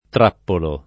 trappolo [ tr # ppolo ]